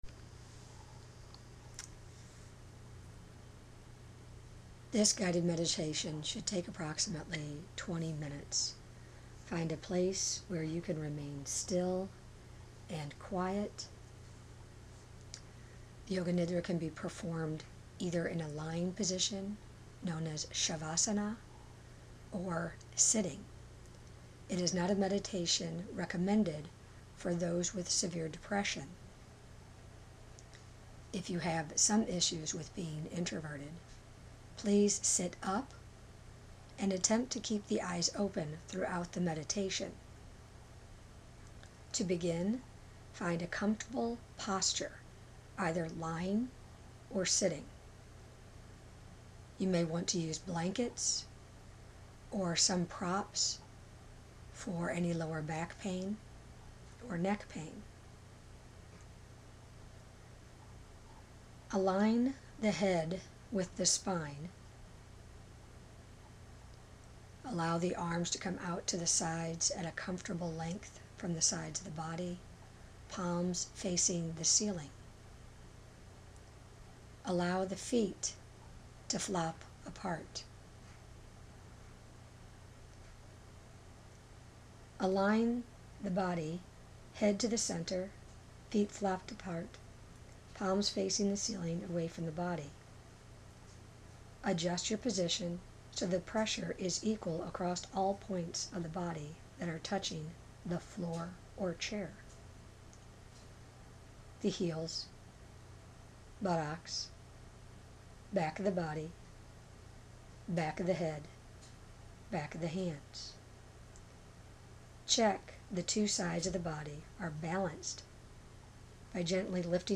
Yoga Nidra 18minutes.mp3